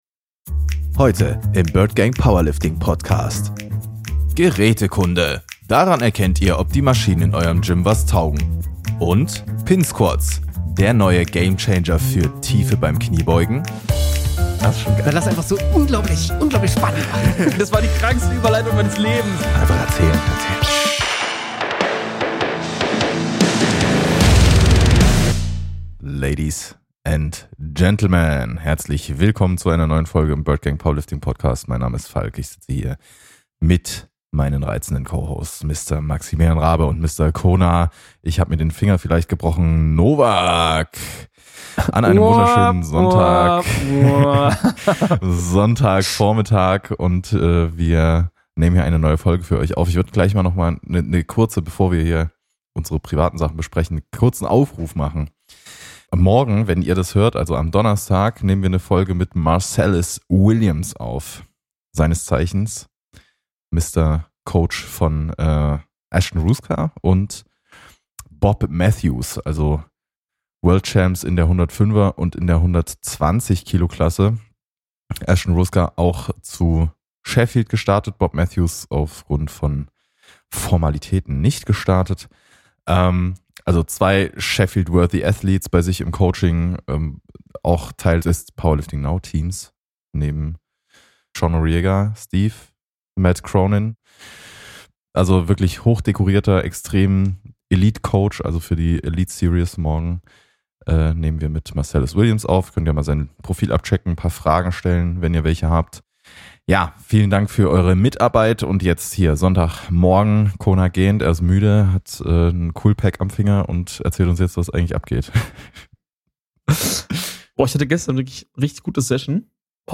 3 Maschinen unterhalten sich über Maschinen. Heute packen wir unsere Meinungen über ein paar Geräte aus und entscheiden im Nachhinein ob Pin Squats ihre Renaissance erleben dürfen.